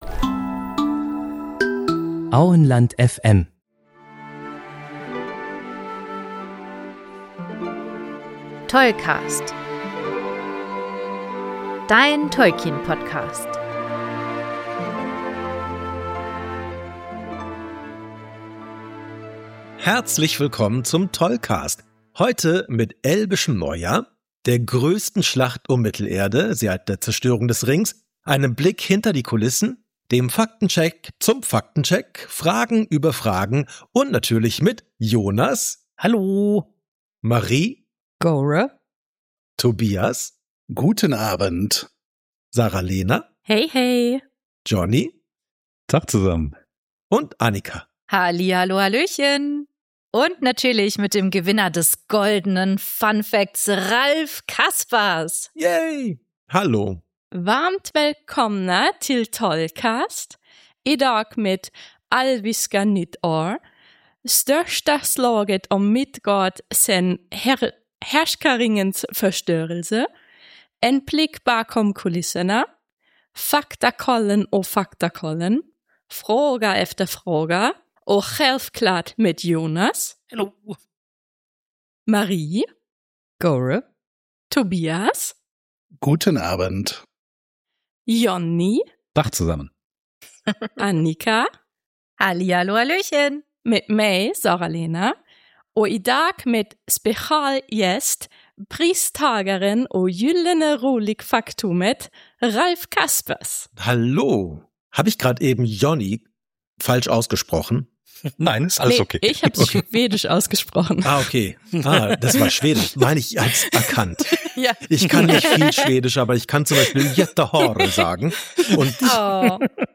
Eine neue Stimme direkt in der Begrüßung?